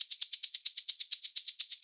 Index of /90_sSampleCDs/USB Soundscan vol.36 - Percussion Loops [AKAI] 1CD/Partition B/22-130SHAKER